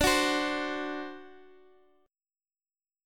D7 Chord
Listen to D7 strummed